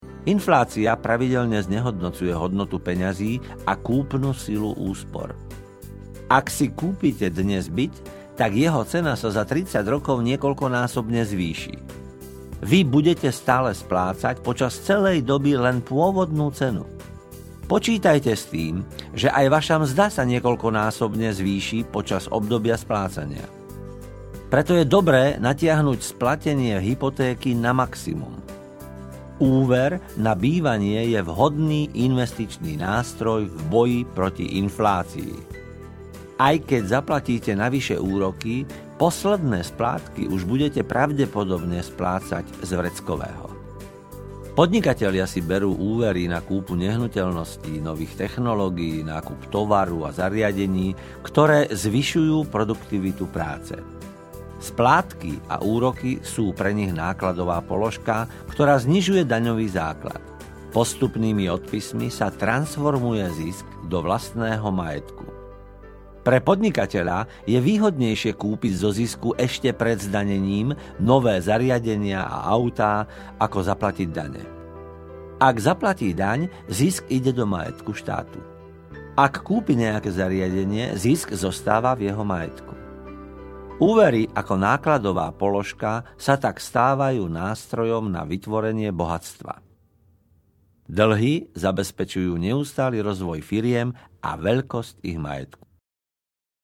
Dlh náš každodenný - Ako sa zbaviť dlhov? audiokniha
Ukázka z knihy